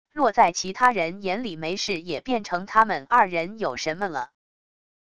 落在其他人眼里没事也变成他们2人有什么了wav音频